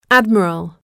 단어번호.0615 대단원 : 3 소단원 : a Chapter : 03a 직업과 사회(Work and Society)-Professions(직업) admiral [ǽdmərəl] 명) 해군 대장, 제독 mp3 파일 다운로드 (플레이어바 오른쪽 아이콘( ) 클릭하세요.)